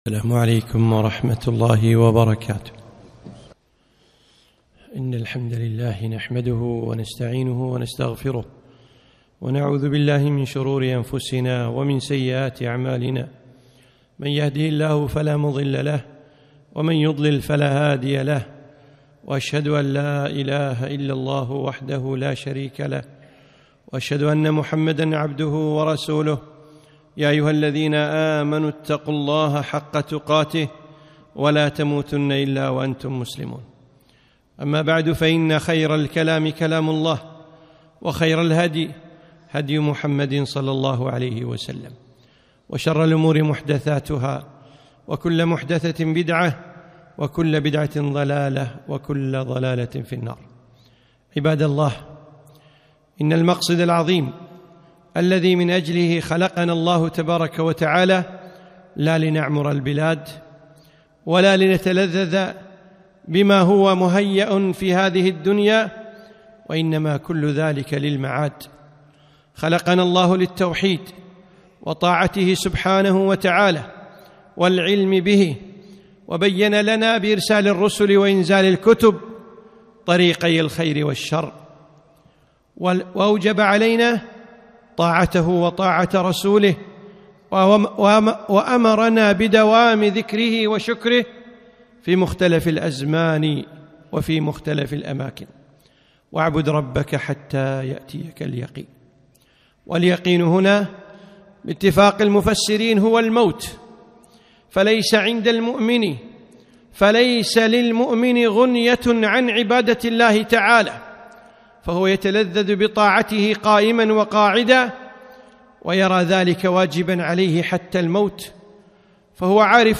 خطبة - خاتمة رمضان